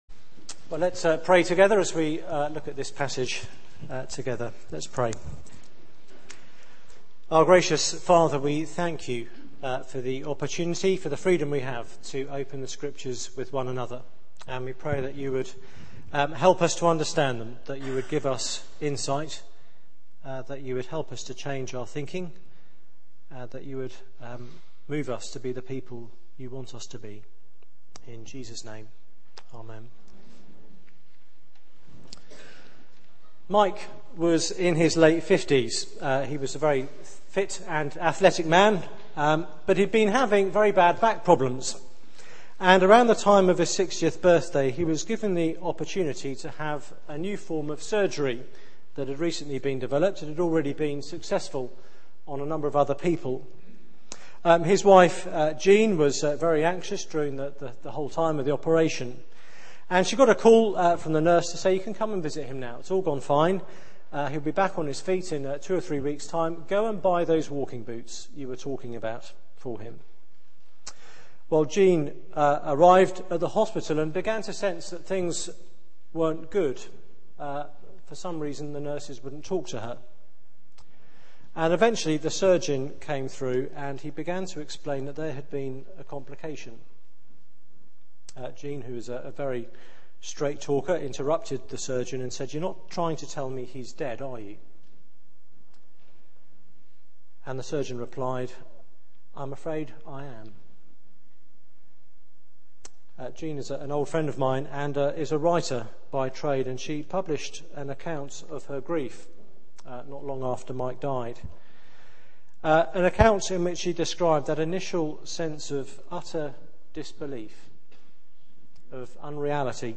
Media for 9:15am Service on Sun 13th Feb 2011 09:15 Speaker
Passage: Ruth 1 Series: From Famine to Fortune Theme: Discovering God's Kindness Sermon